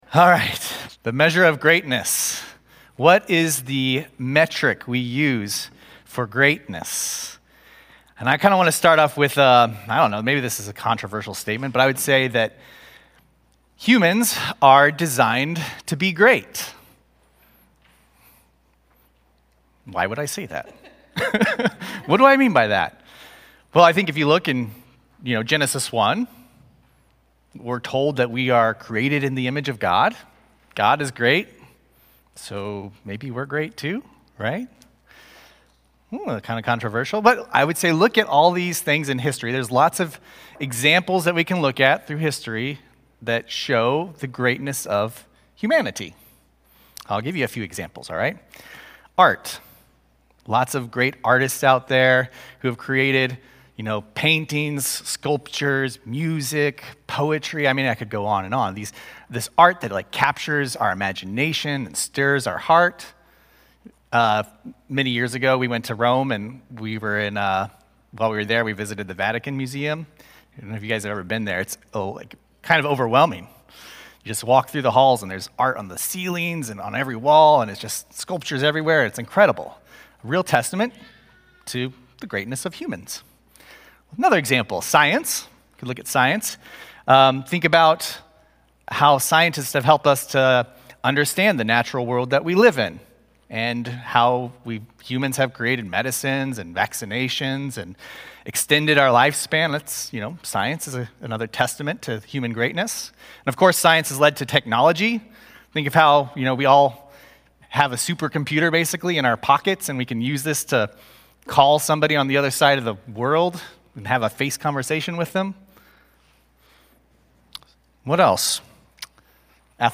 A message from the series "The Real Jesus."